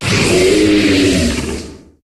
Cri de Trépassable dans Pokémon HOME.